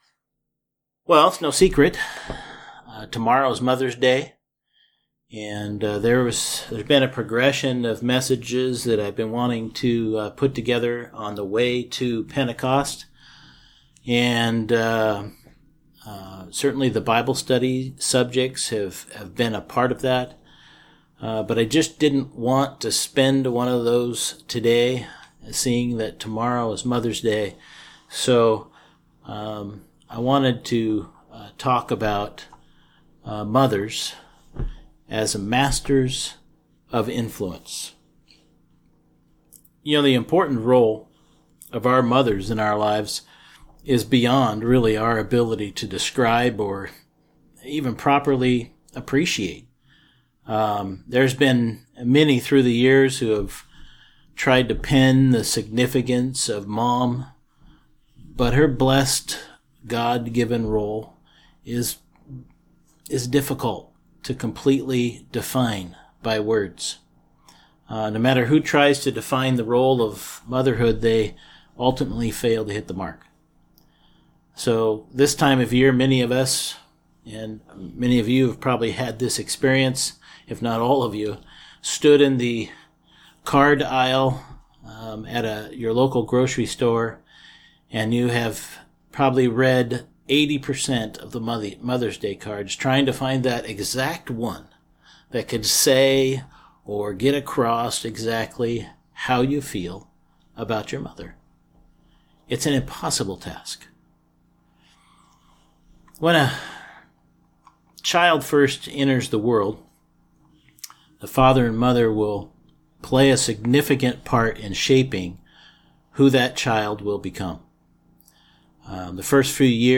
Given in Seattle, WA